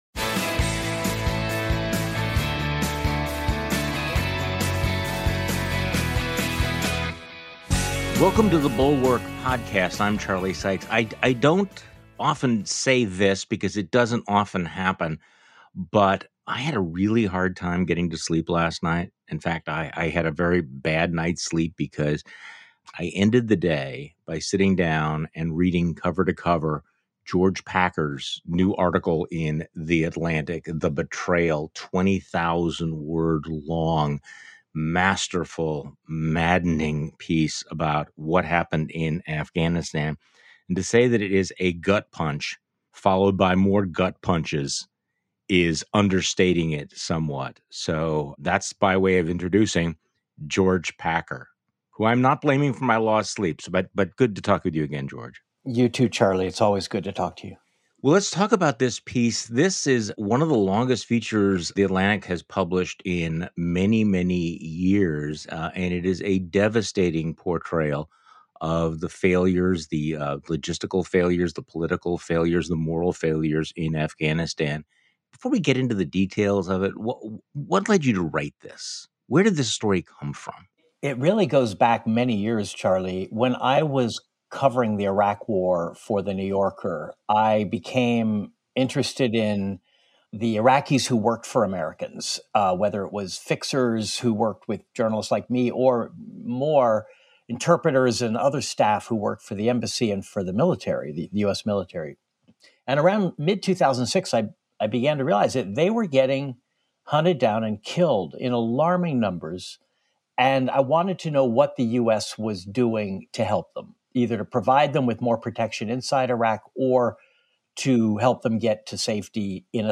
The Atlantic's George Packer joins Charlie Sykes on today's podcast.